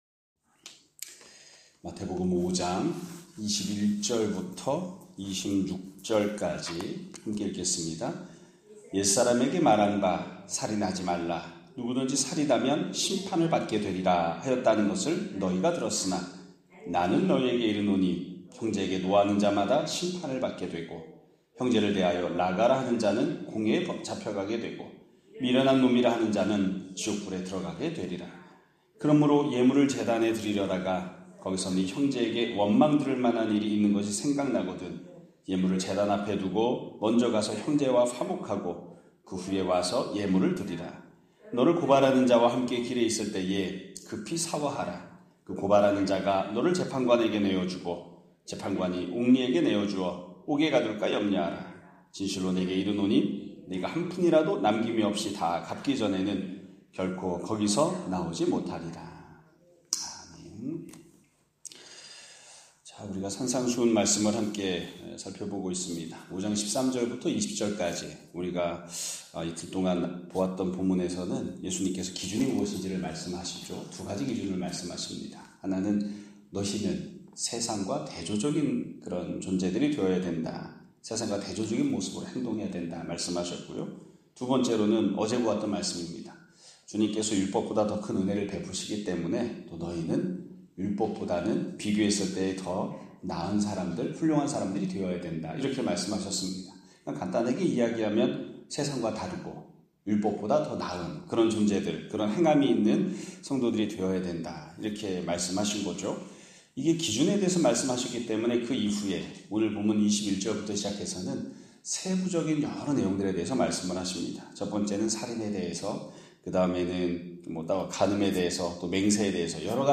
2025년 5월 22일(목요일) <아침예배> 설교입니다.